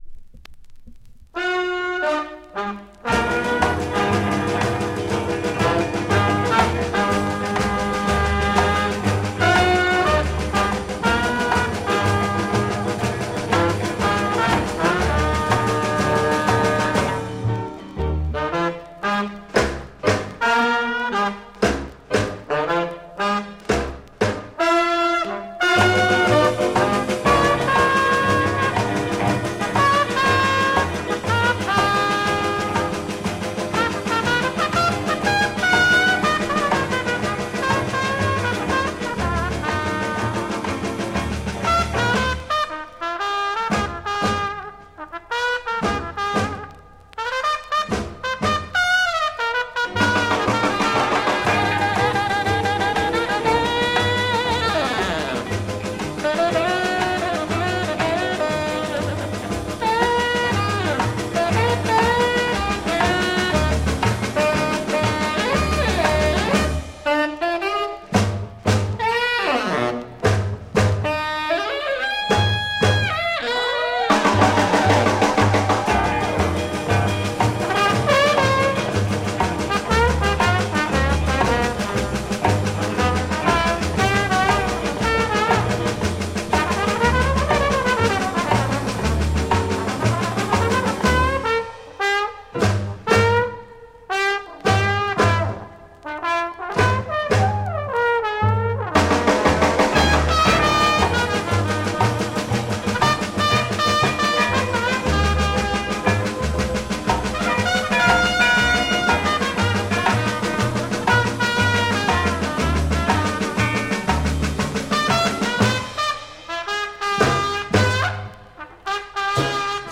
French Rock'n'roll Jazz
4 instrumental rock'ab